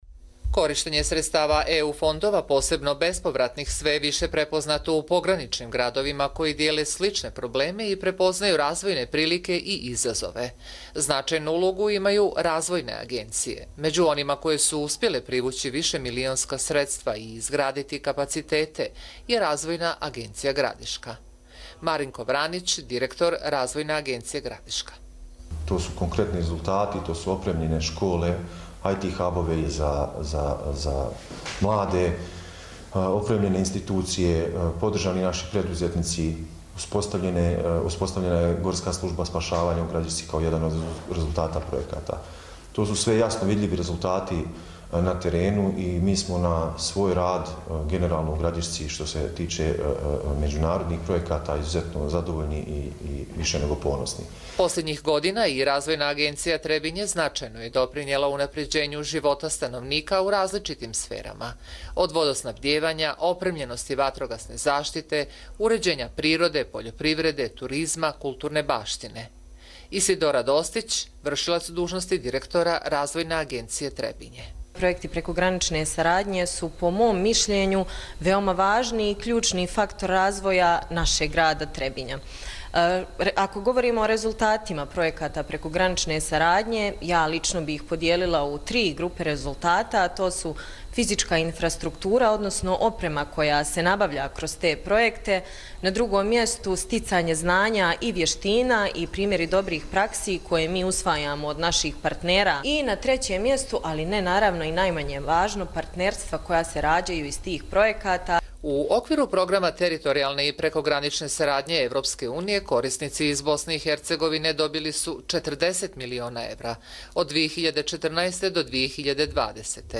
Радио репортажа